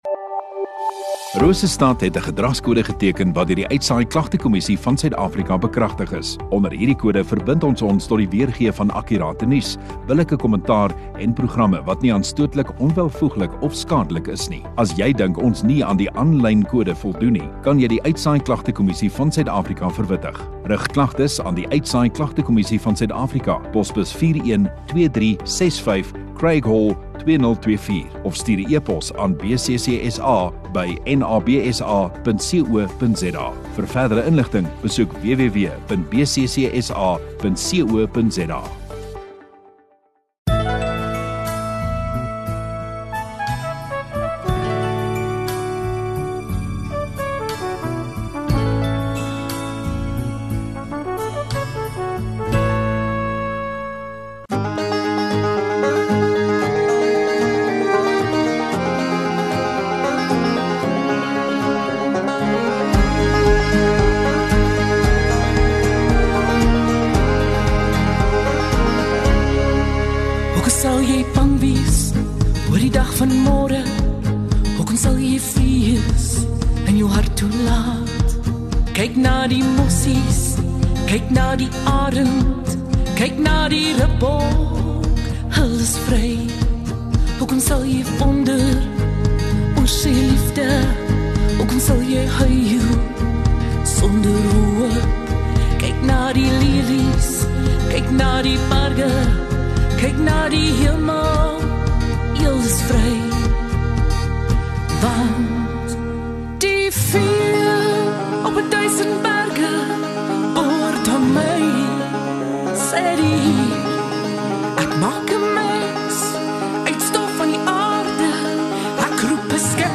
23 Jun Sondagoggend Erediens